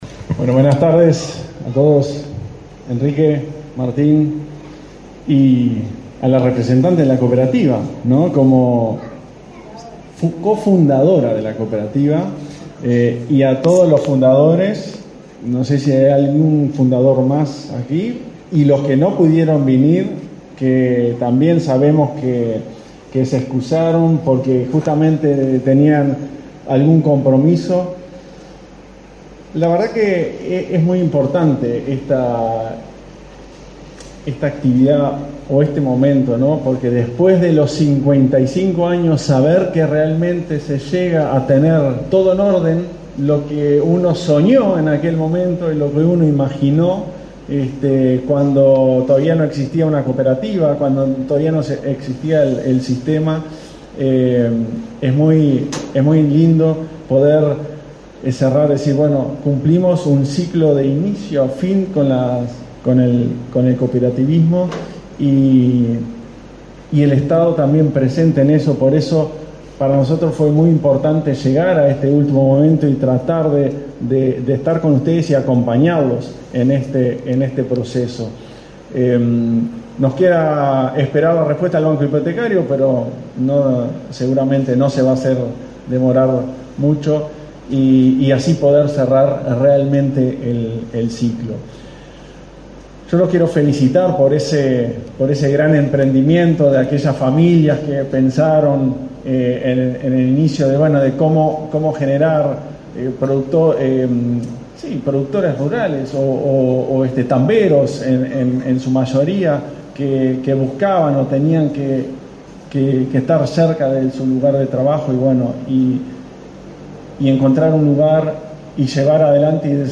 Palabras del director de la ANV, Klaus Mill
En el evento disertó el director de la ANV, Klaus Mill.